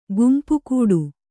♪ gumpu kūḍu